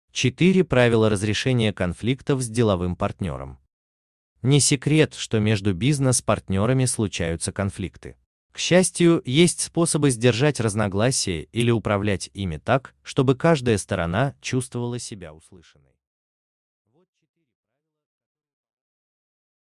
Аудиокнига 4 правила разрешения конфликтов с деловым партнером | Библиотека аудиокниг